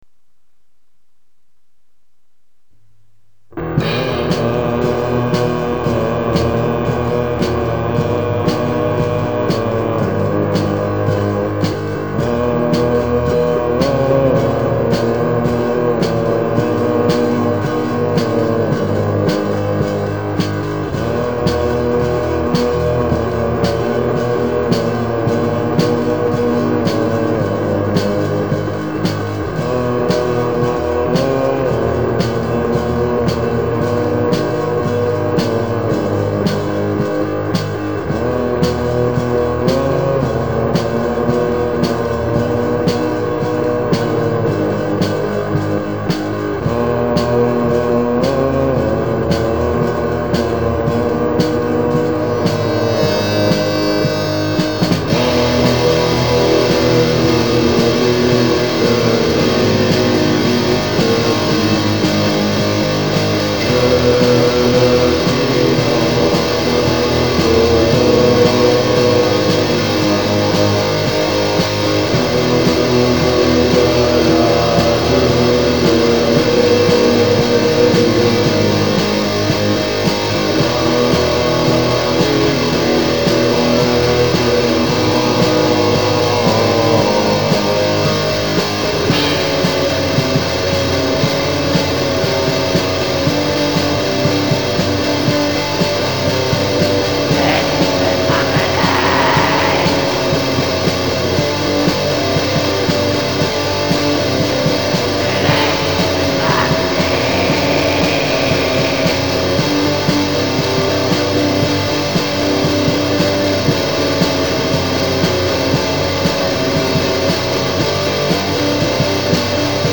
*фоновая композиция